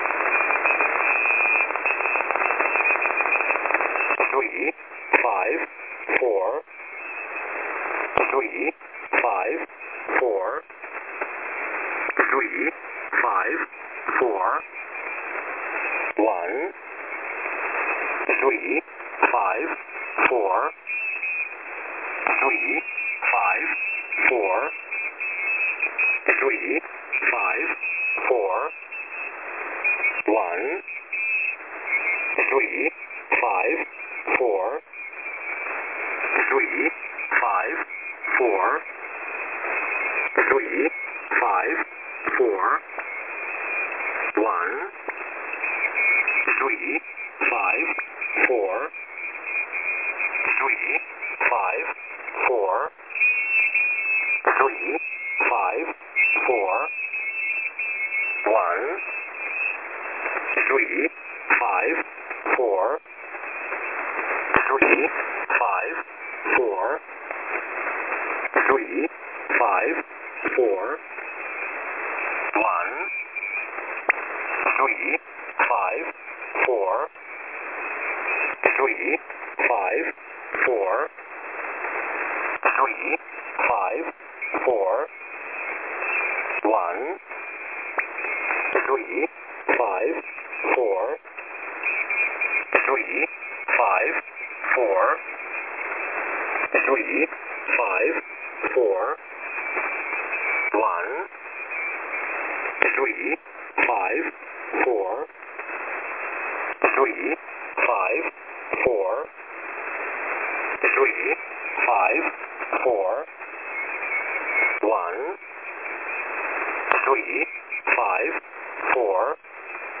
Mode: USB
Retransmission of the 17:00UTC sequence.